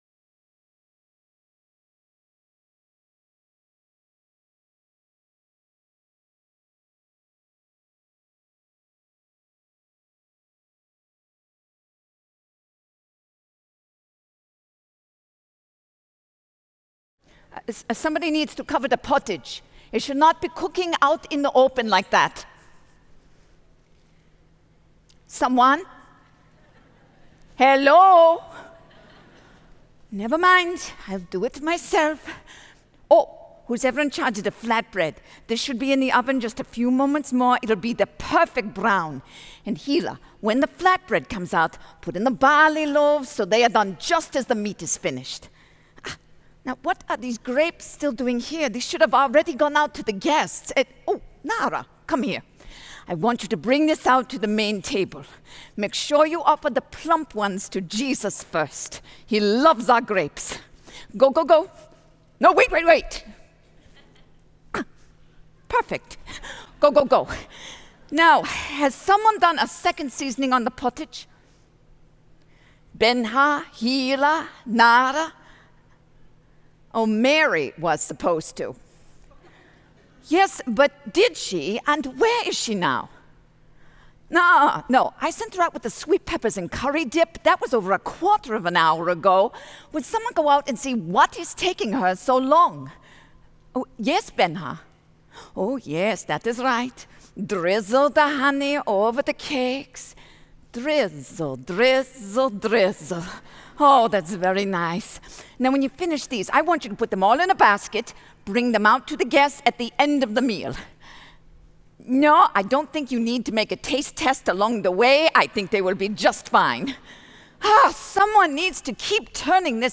Message 14 (Drama): The Good Part